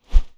Close Combat Swing Sound 8.wav